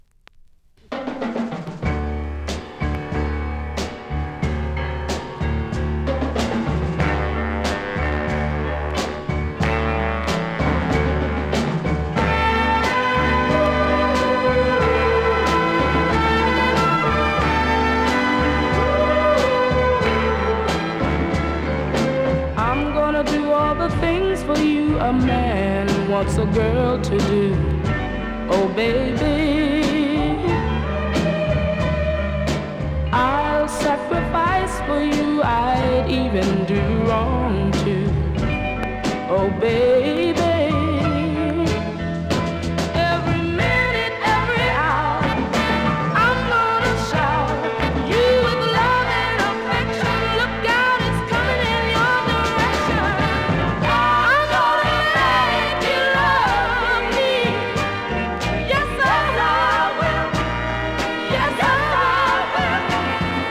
40秒頃3発ノイズありますので試聴で確認下さい。